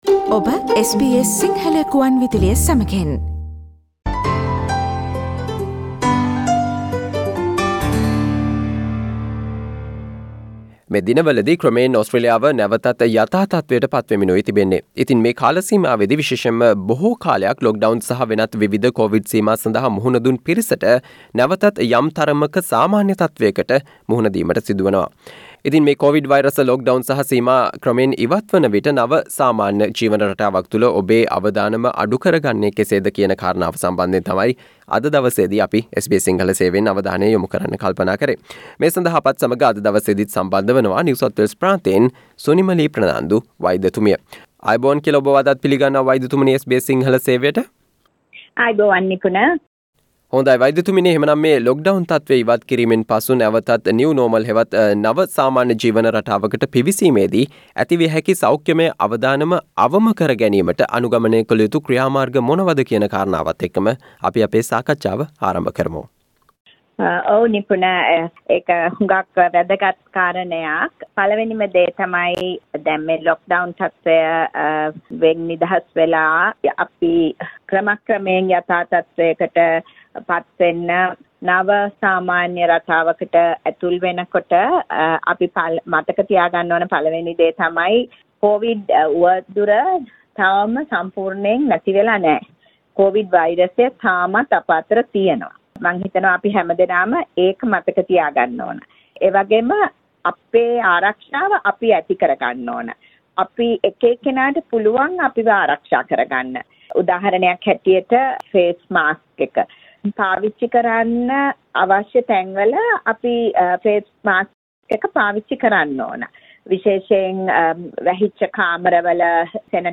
New Normal හෙවත් "නව සාමාන්‍ය" ජීවන රටාවක් තුල ඔබේ අවදානම අඩු කර ගන්නේ කෙසේද යන්න පිළිබඳව පිළිබඳව SBS සිංහල සේවය සිදු කල සාකච්චාවට සවන්දෙන්න